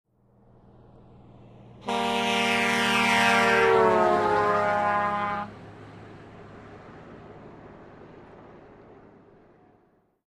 Гудок воздушного сигнала грузового транспорта